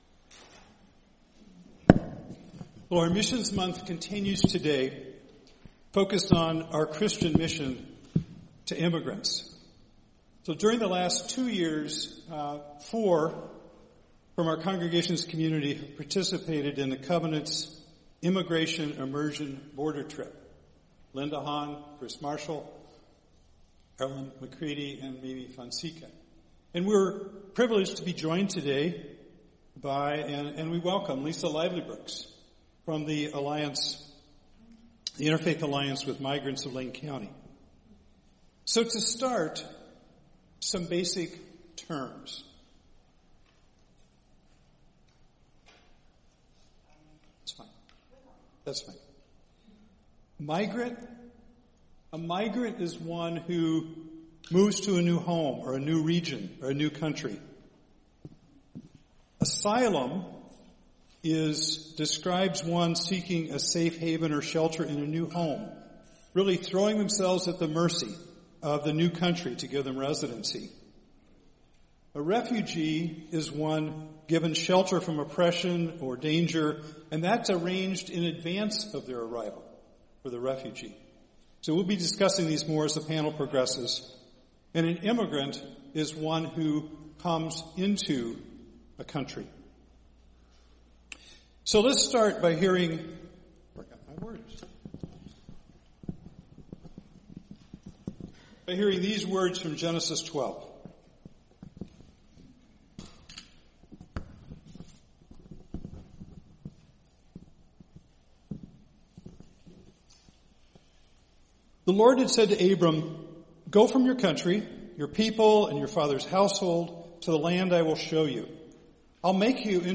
Immigration Panel